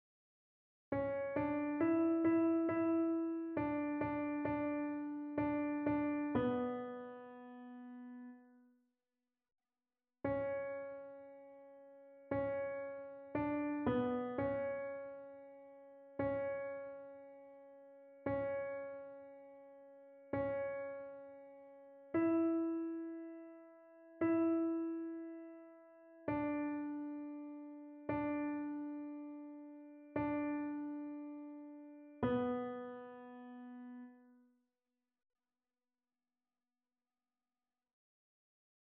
annee-abc-temps-de-noel-nativite-du-seigneur-psaume-88-alto.mp3